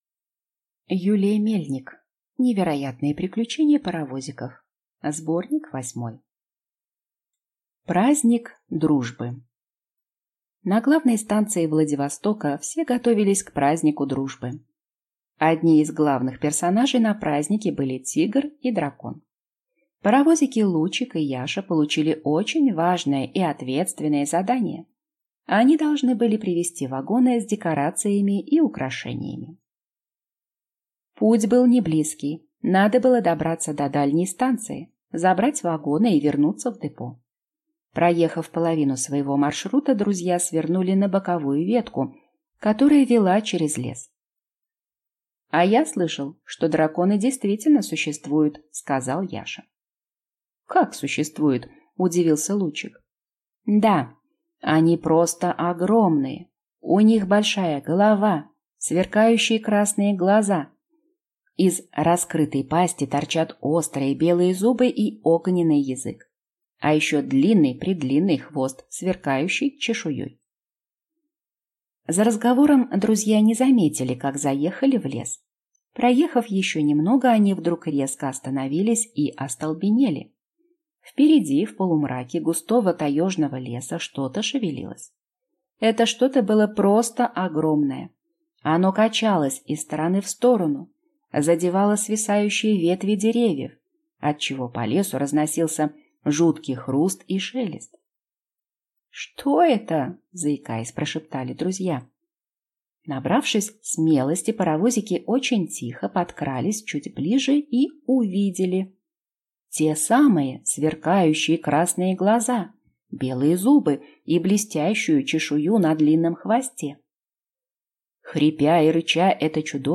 Аудиокнига Невероятные приключения паровозиков. Сборник 8 | Библиотека аудиокниг